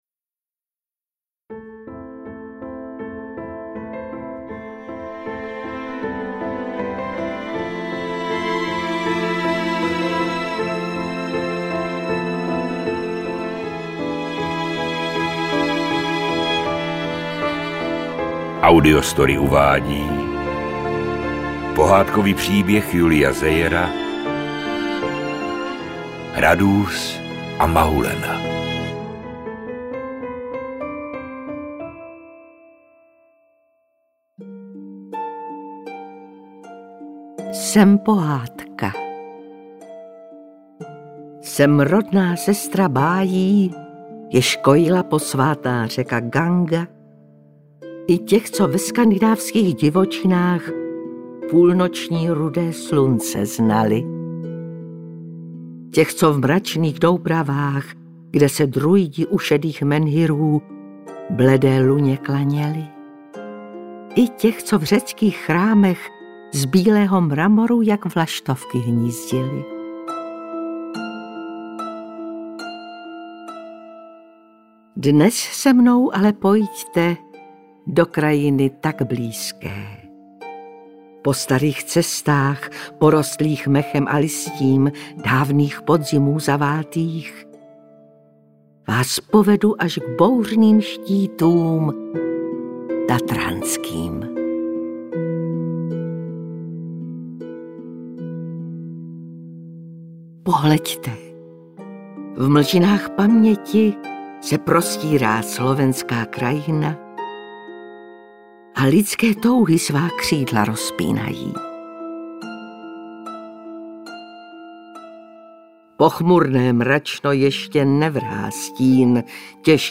Radúz a Mahulena audiokniha
Ukázka z knihy